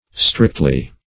Strictly \Strict"ly\, adv.